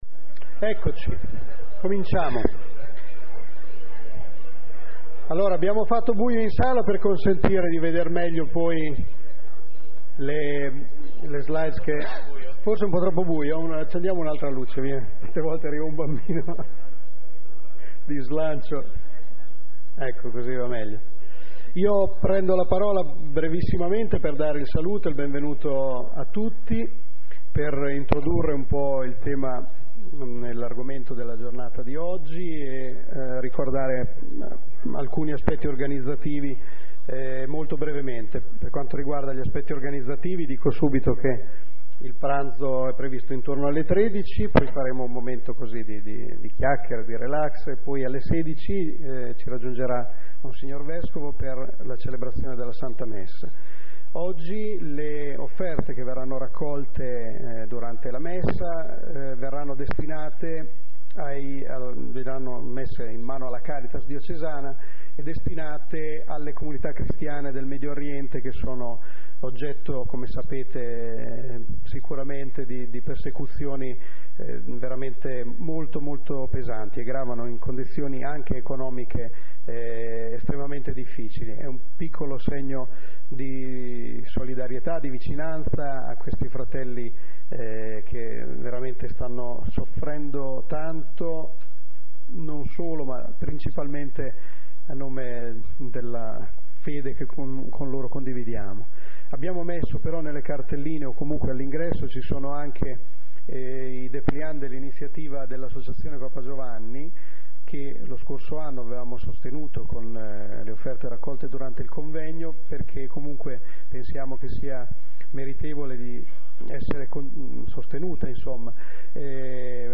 Teatro Parrocchiale Novafeltria
12° Convegno Famiglie